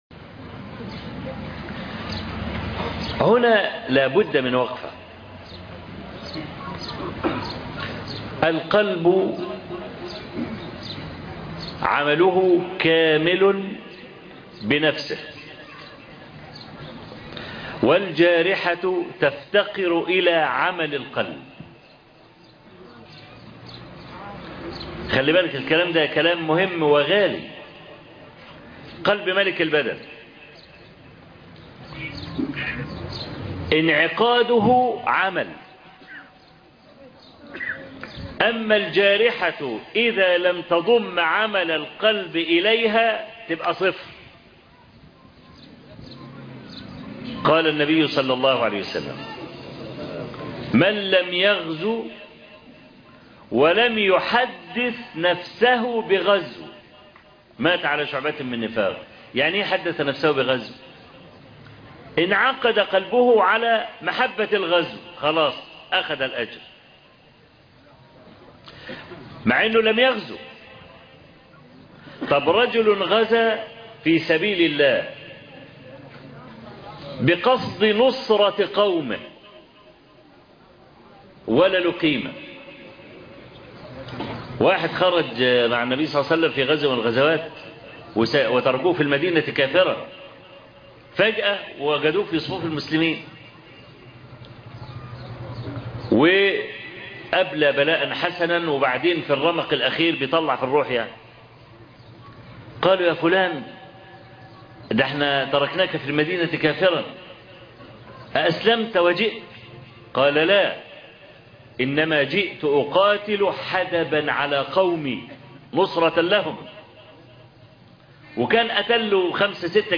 هل نحتفل مع النصارى بأعيادهم؟- شرح مبسط